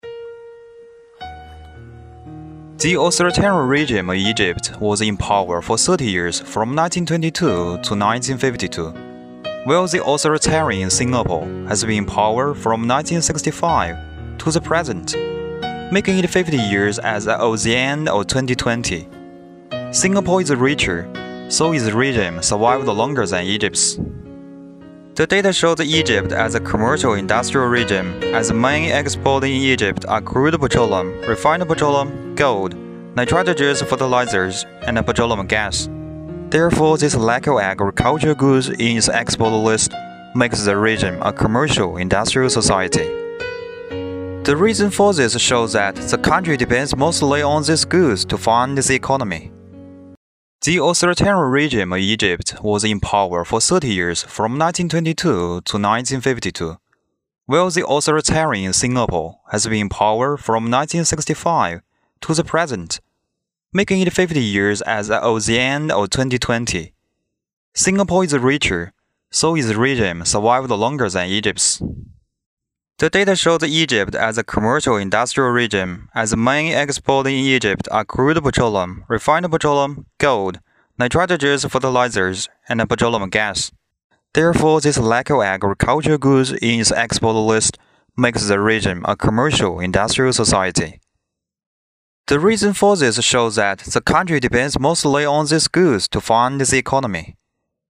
PPT讲解